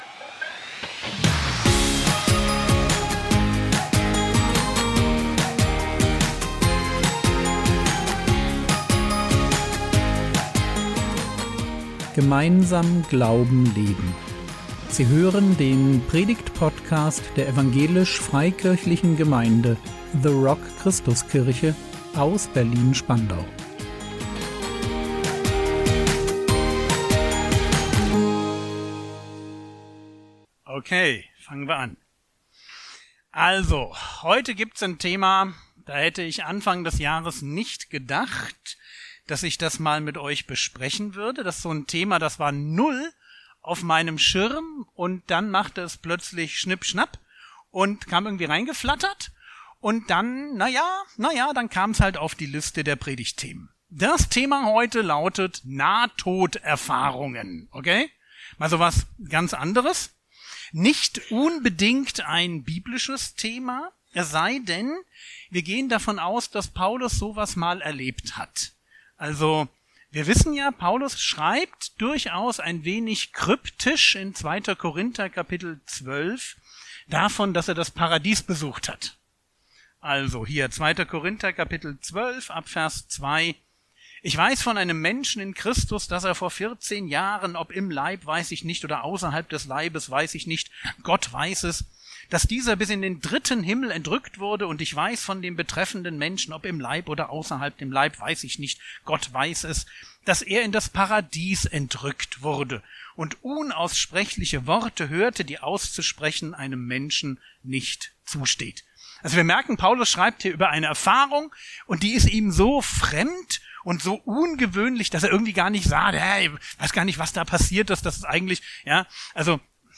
Nahtoderfahrungen | 01.06.2025 ~ Predigt Podcast der EFG The Rock Christuskirche Berlin Podcast